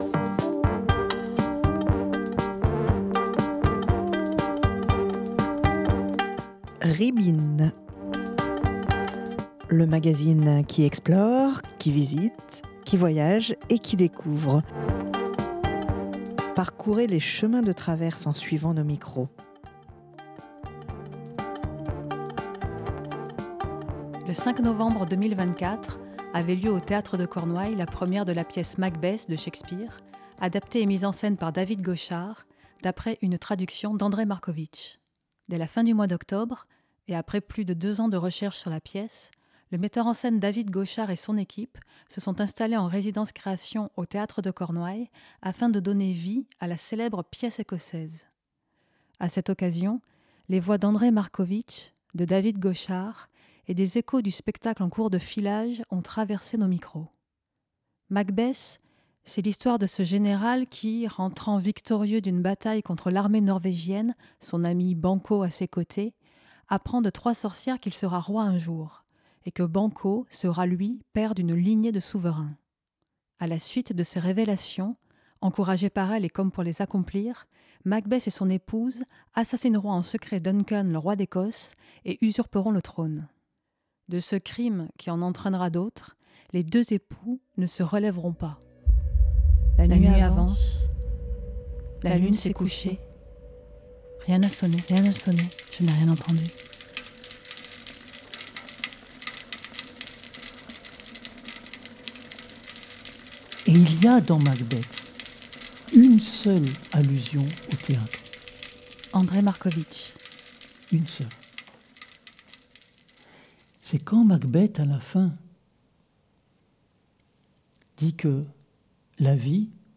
des échos du spectacle en cours de filage ont traversé nos micros. De ces rencontres, de ces écoutes sont nés deux documentaires : un Ribines de 24 minutes et une version longue